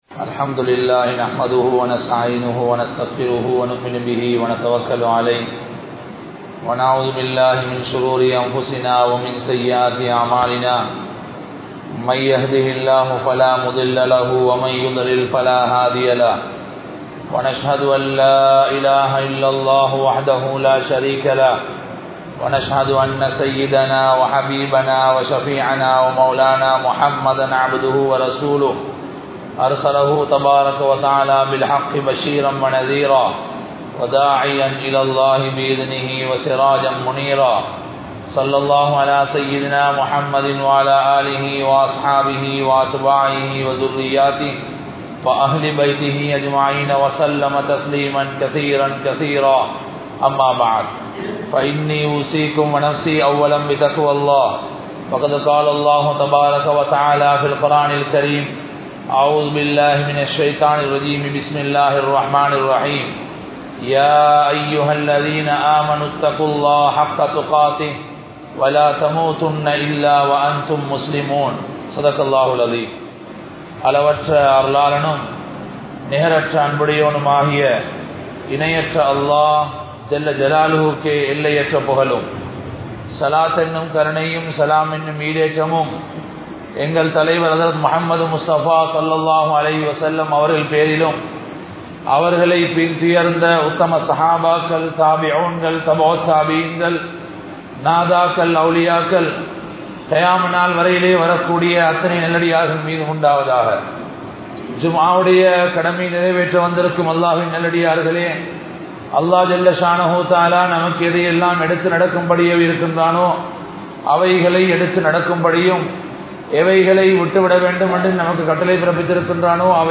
Pari Pooranmaana Maarkam ISLAM (பரிபூர்ணமான மார்க்கம் இஸ்லாம் ) | Audio Bayans | All Ceylon Muslim Youth Community | Addalaichenai